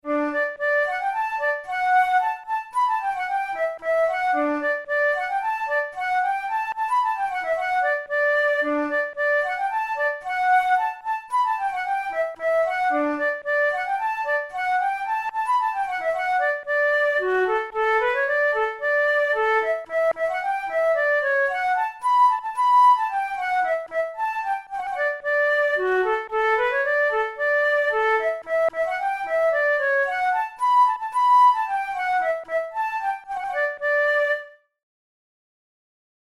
Traditional Scottish reel
We transposed the melody from C major to D major to better fit the range of the flute.
Categories: Celtic Music Reels Traditional/Folk Difficulty: easy